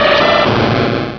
Cri d'Aligatueur dans Pokémon Rubis et Saphir.